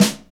Index of /90_sSampleCDs/Northstar - Drumscapes Roland/DRM_Motown/KIT_Motown Kit1x
SNR MTWN 03R.wav